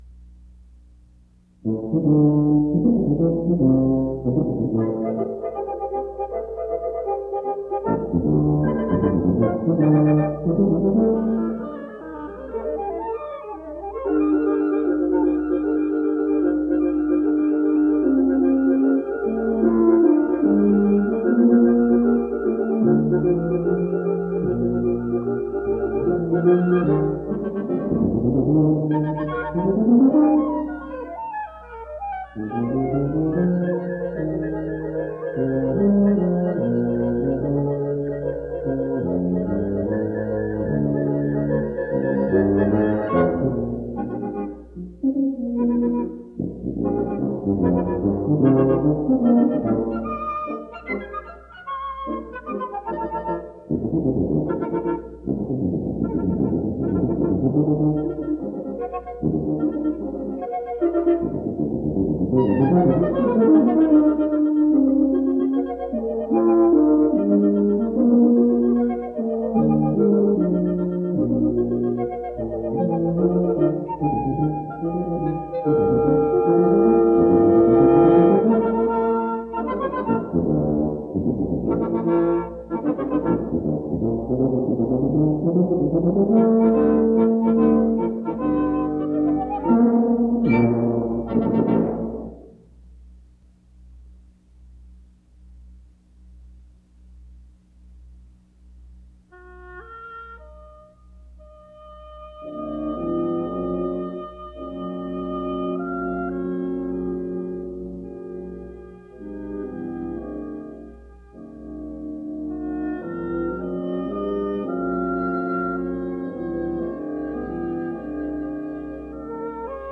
Quintet